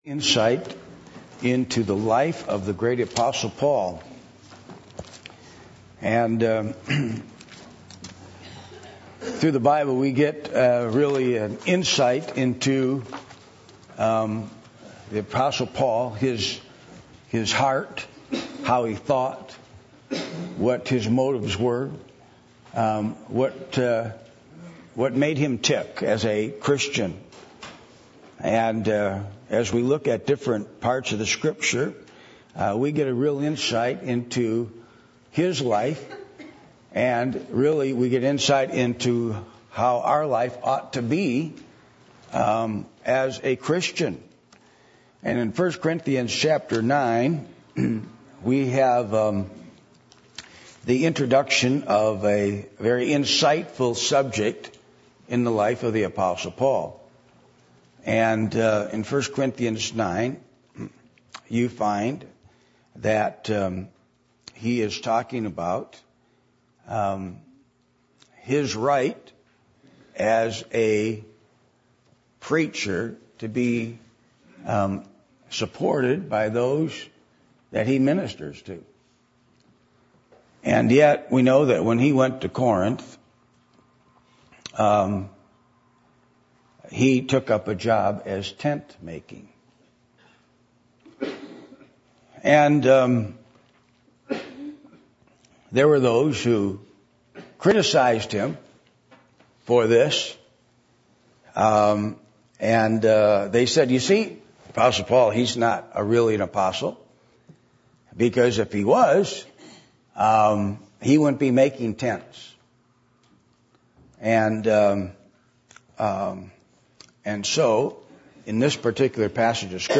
Passage: 1 Corinthians 9:16-23 Service Type: Sunday Morning